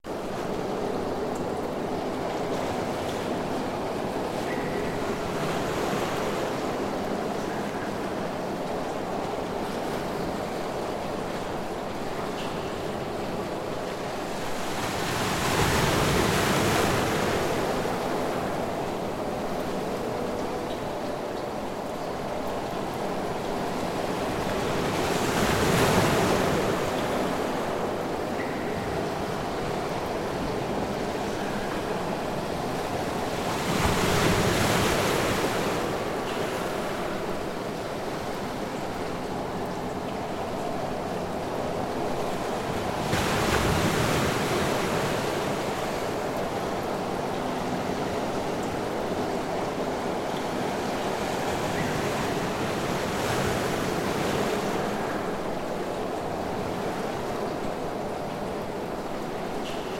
Пиратский корабль бороздит морские просторы